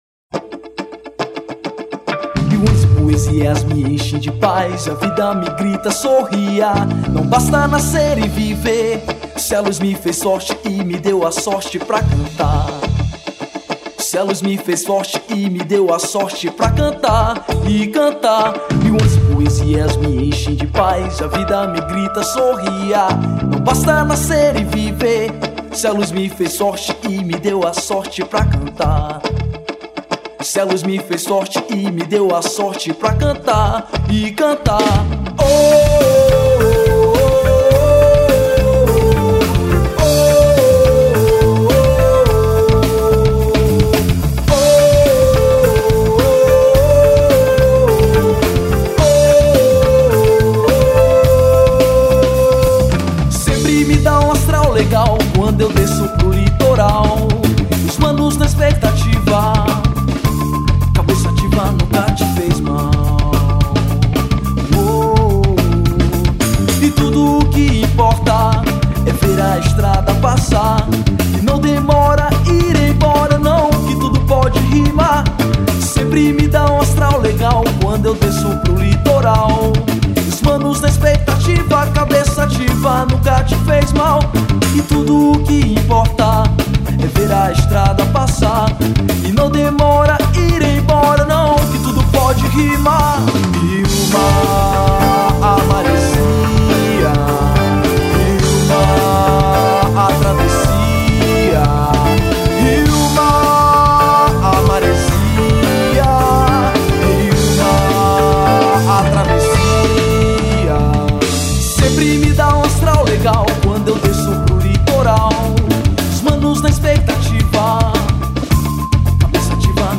2773   04:39:00   Faixa: 7    Rock Nacional